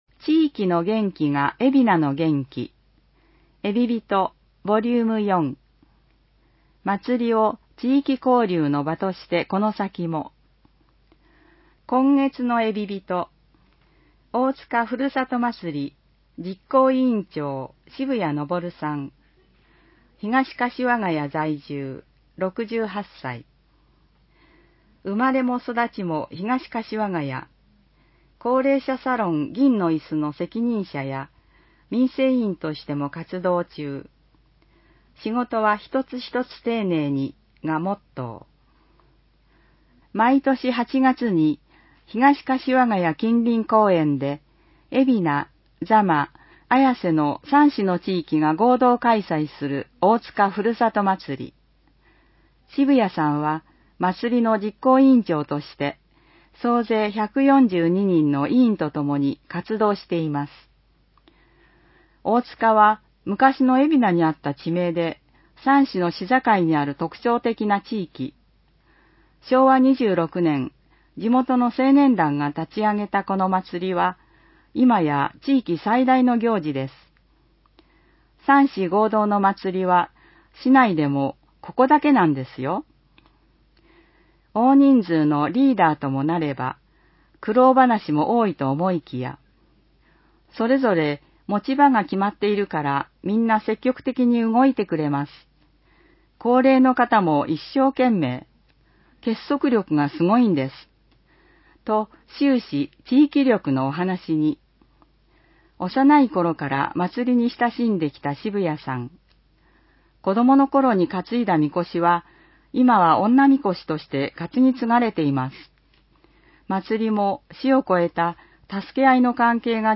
広報えびな 平成30年4月15日号（電子ブック） （外部リンク） PDF・音声版 ※音声版は、音声訳ボランティア「矢ぐるまの会」の協力により、同会が視覚障がい者の方のために作成したものを登載しています。